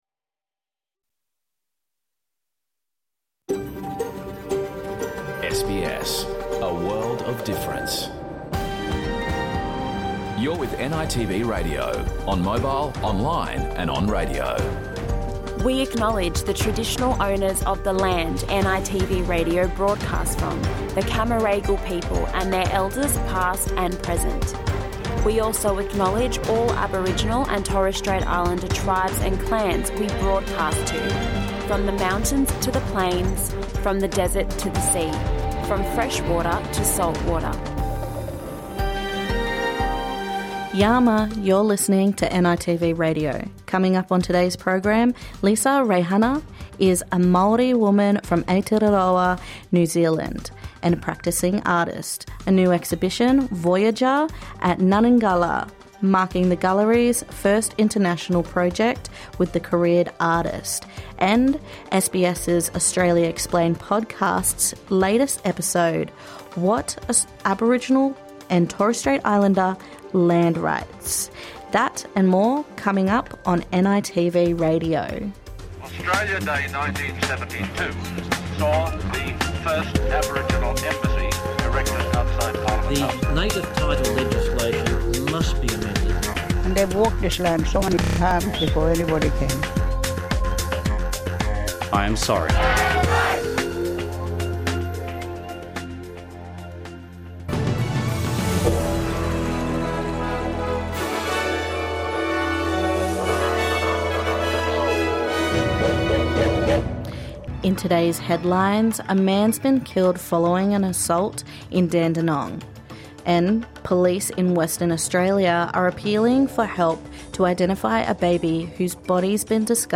The latest episode - What are Aboriginal and Torres Strait Islander Land Rights? That and more on NITV radio.